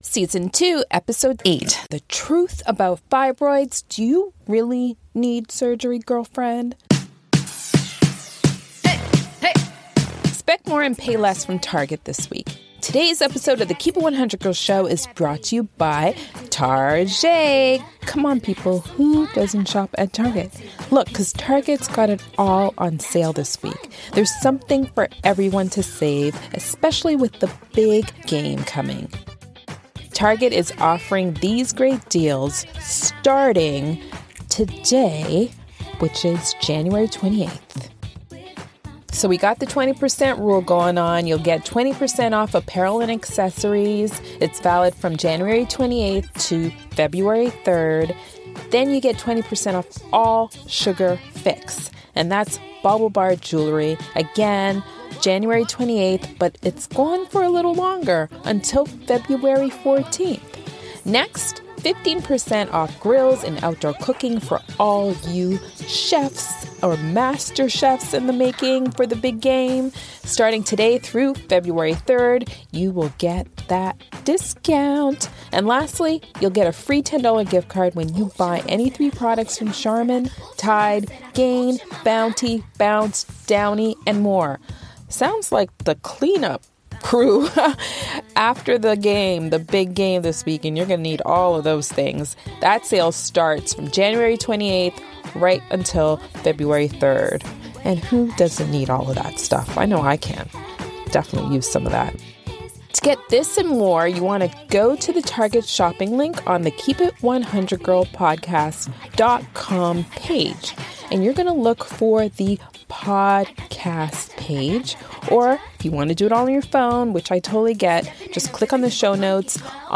Nothing is off limits in this raw, real, and emotional conversation. This episode is for those that want to learn more about the natural ways to cure fibroids.